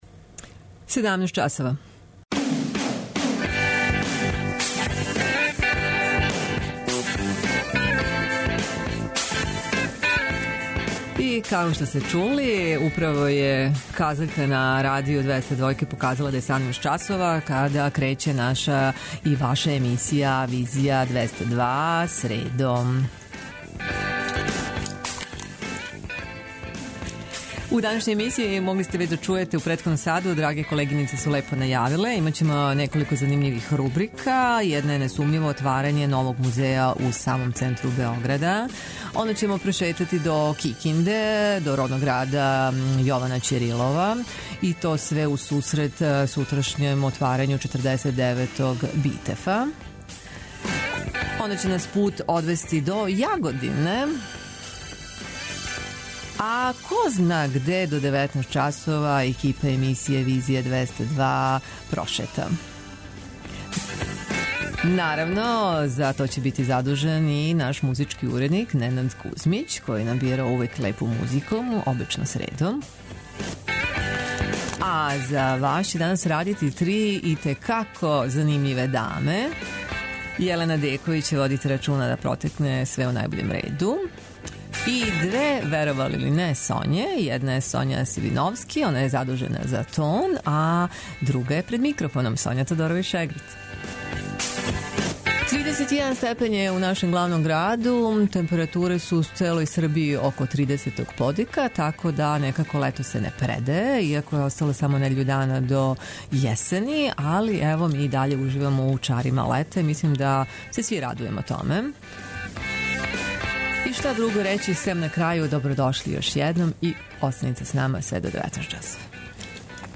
У наставку емисије чућете причу коју смо забележили у Кикинди, родном граду Јована Ћирилова, једног од оснивача БИТЕФ ФЕСТИВАЛА који почиње сутра у Народном позоришту по 49. пут.
преузми : 56.14 MB Визија Autor: Београд 202 Социо-културолошки магазин, који прати савремене друштвене феномене.